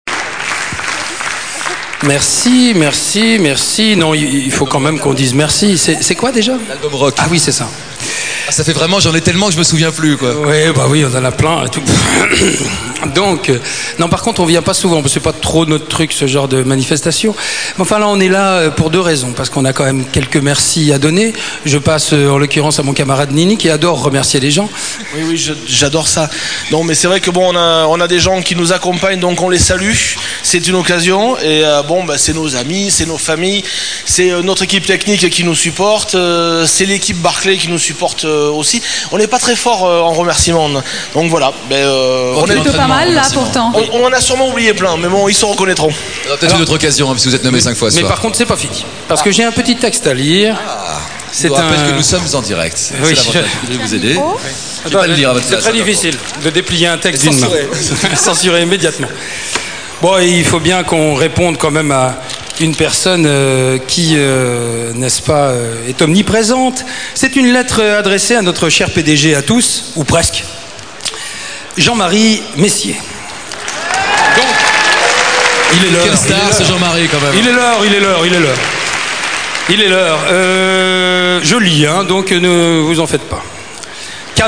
Le discours de Noir Desir aux Victoires de la musique adresse a Jean Marie Messier :
format mp3 - discours